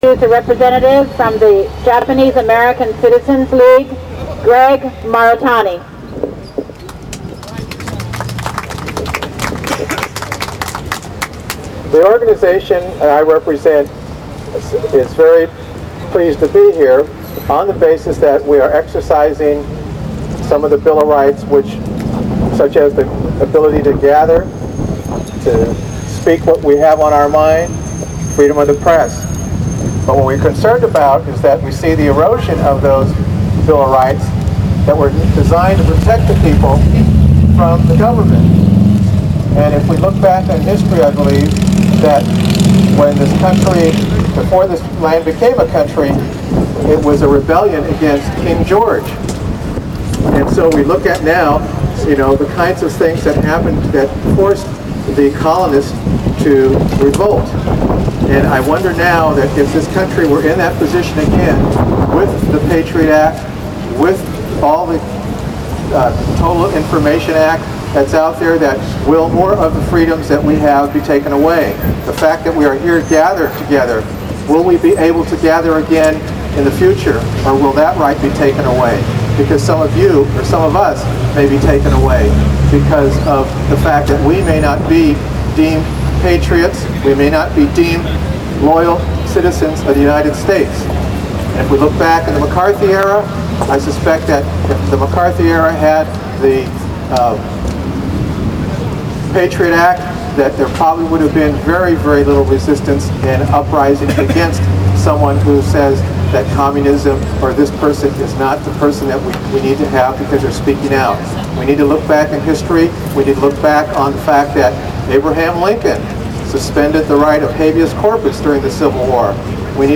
This footage is from the protest in front of the INS building that took place from noon to 1pm at 444 Washington Street in San Francisco on June 13, 2003.